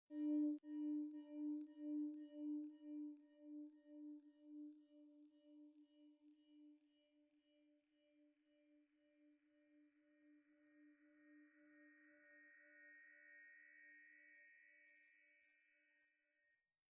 Den nedenstående illustration og det tilhørende lydeksempel viser et eksponentielt voksende feedback i slutningen af forløbet der næsten ikke kan høres. De fleste af de frekvenser der looper i feedbacket ligger over det hørbare område.
feedbackofconsciousness.wma